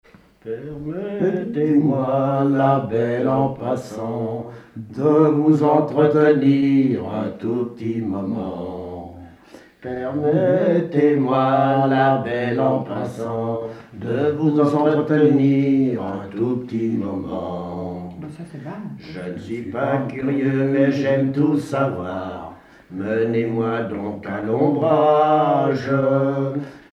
Pièce musicale inédite